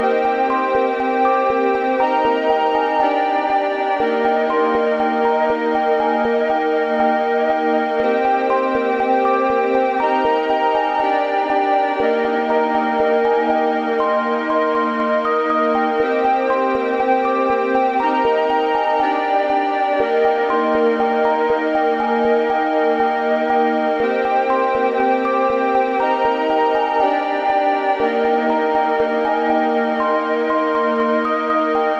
标签： 120 bpm LoFi Loops Synth Loops 5.38 MB wav Key : Unknown Ableton Live
声道立体声